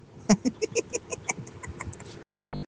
Laugh Sound Effects MP3 Download Free - Quick Sounds